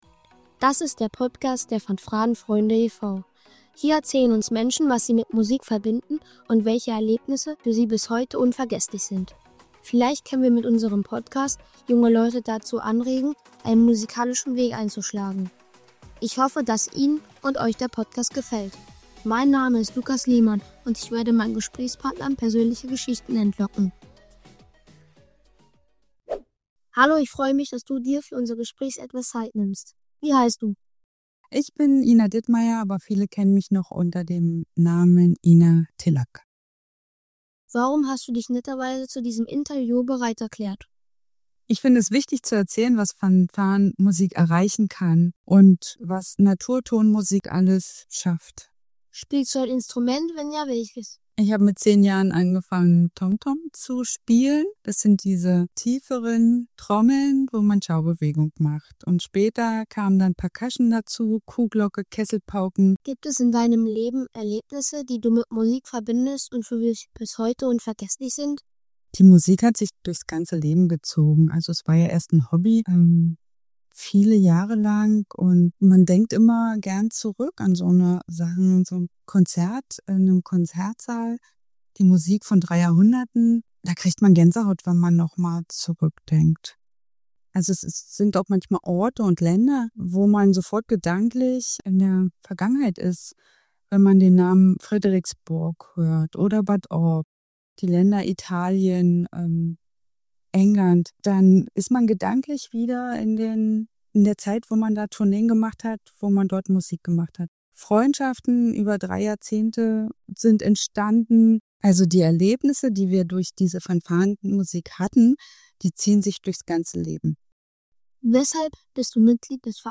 Als Gesprächspartnerin